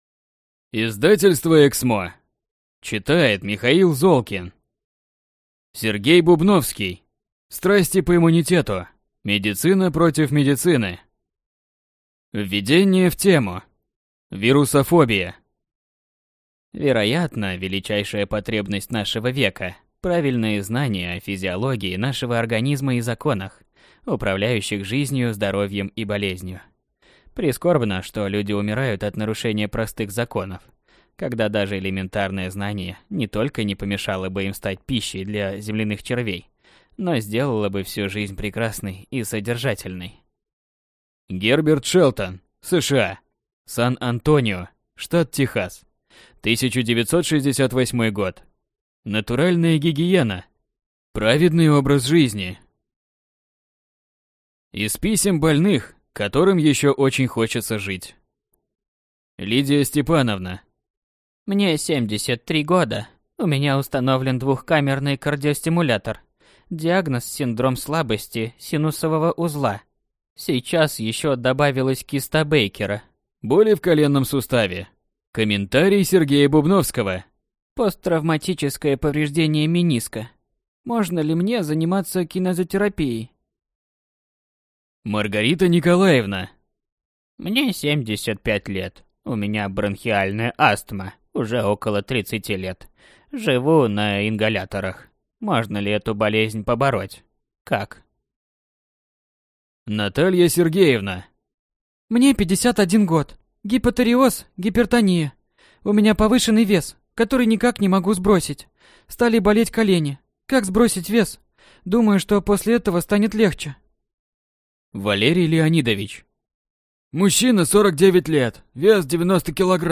Аудиокнига Страсти по иммунитету. Медицина против медицины | Библиотека аудиокниг